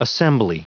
Prononciation du mot assembly en anglais (fichier audio)
Prononciation du mot : assembly